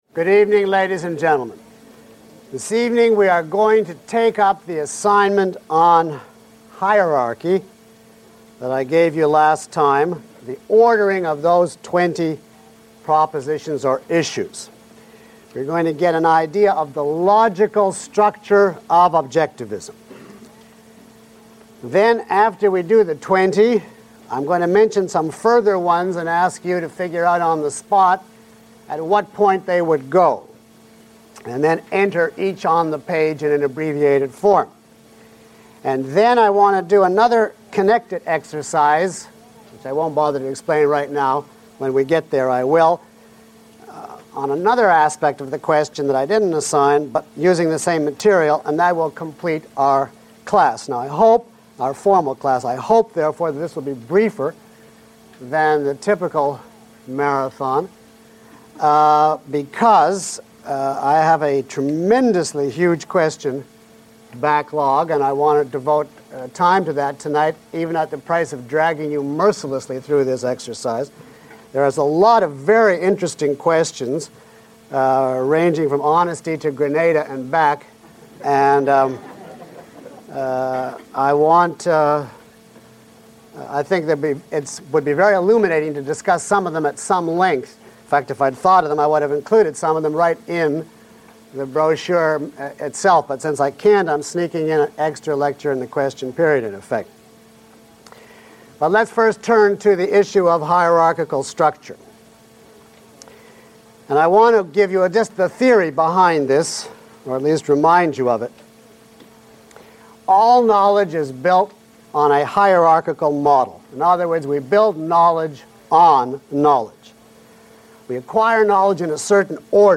This course features lecture material by Dr. Peikoff as well as exercises and demonstrations from the live audience.